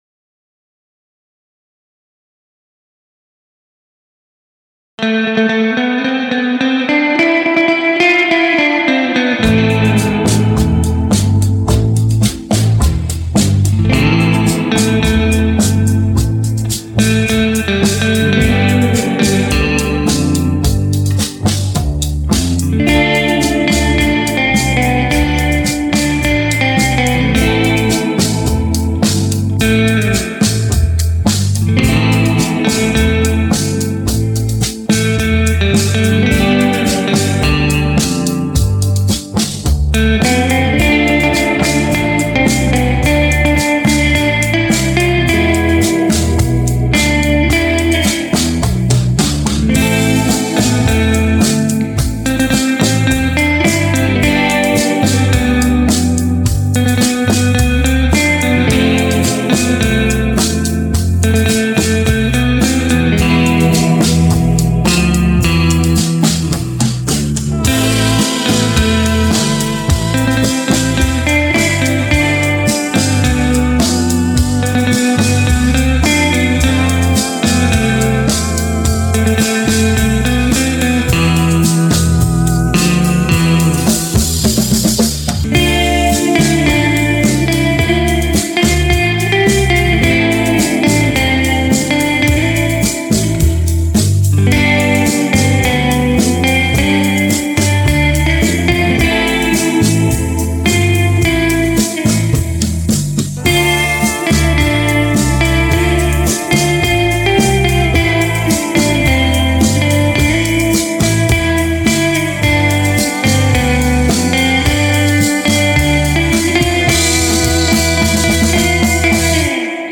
I wrote this tune on the Jazzmaster
I am stuck on the Desert Chill genre these days
My stuff is closer to Spaghetti Western/Surf stuff